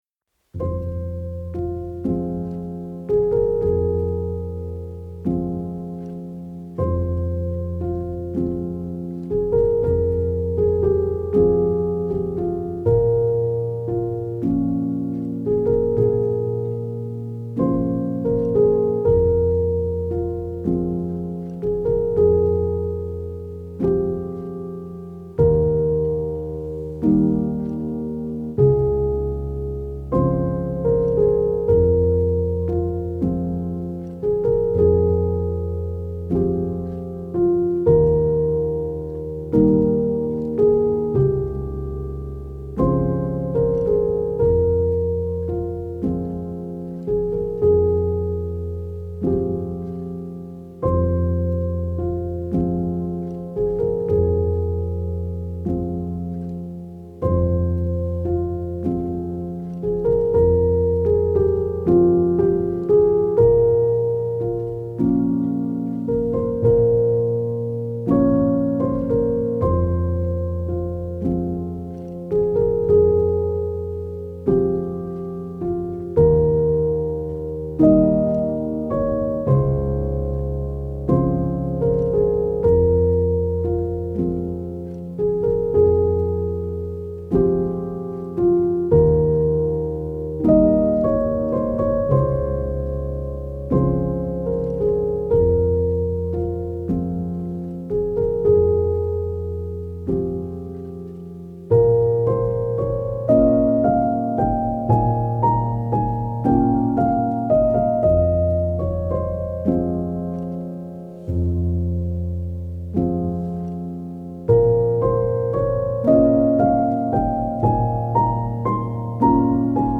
آرامش بخش
Classical Crossover
پیانو
پیانو احساسی
نئو کلاسیک